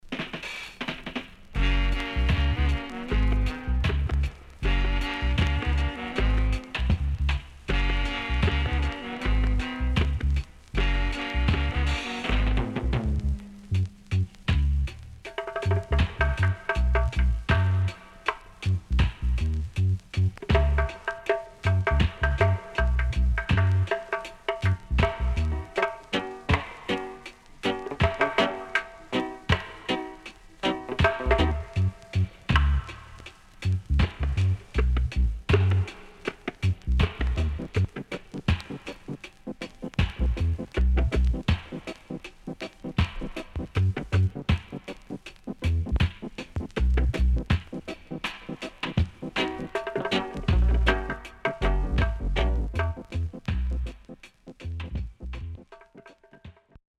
HOME > Back Order [VINTAGE 7inch]  >  INST 70's
SIDE A:薄くヒスノイズあり。